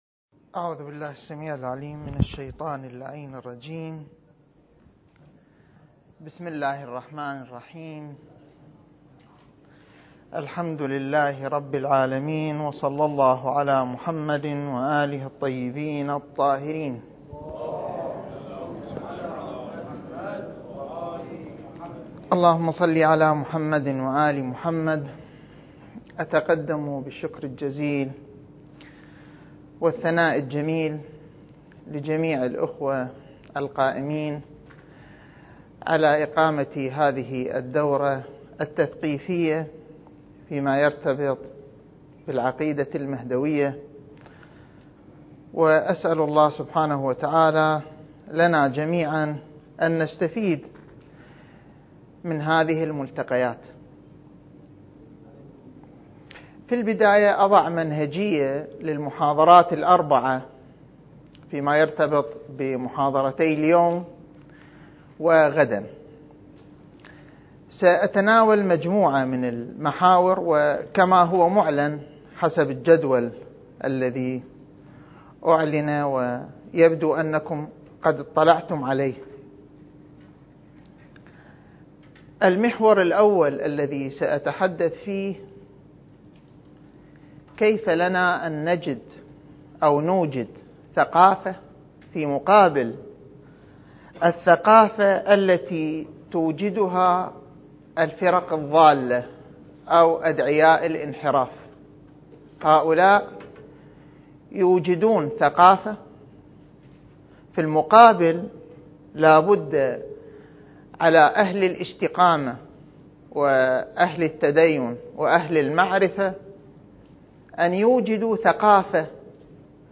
المكان: مؤسسة الإمام الحسن المجتبى (عليه السلام) - النجف الأشرف دورة منهجية في القضايا المهدوية (رد على أدعياء المهدوية) (13) التاريخ: 1443 للهجرة